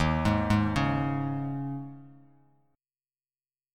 D#7sus4 chord